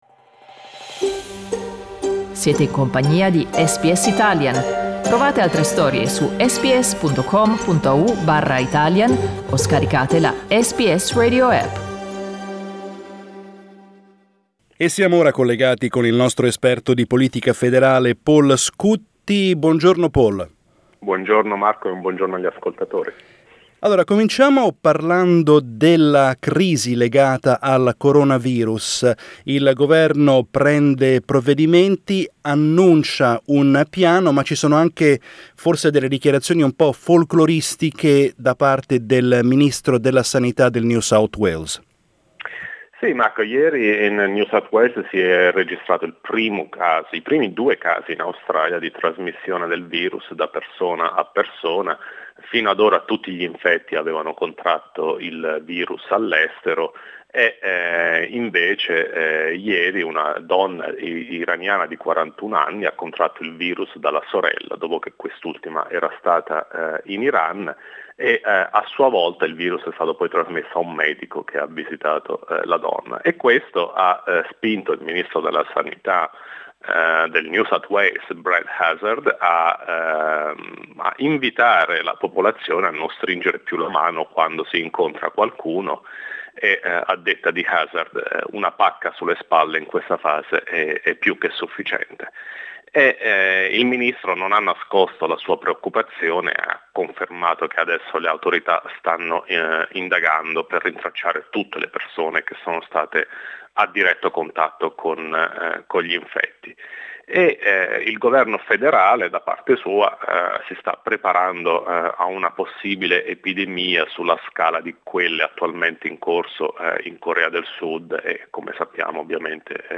Ne abbiamo parlato con il nostro esperto di politica federale australiana